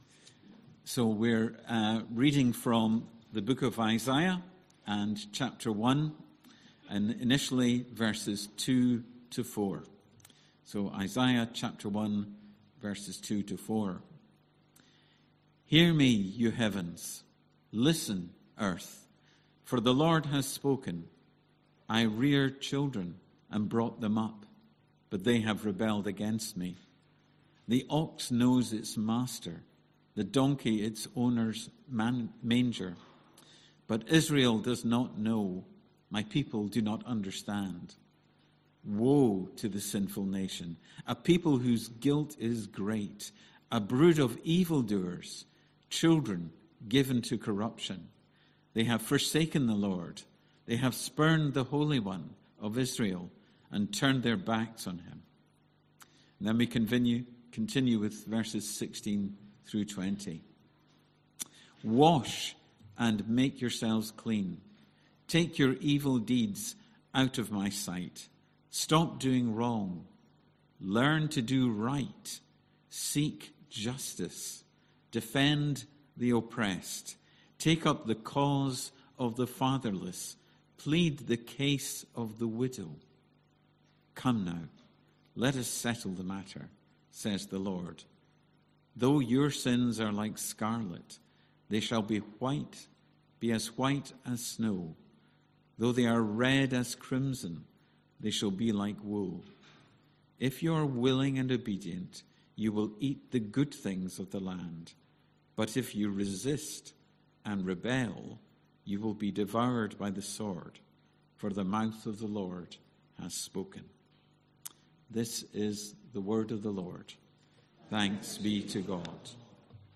20th July 2025 Sunday Reading and Talk - St Luke's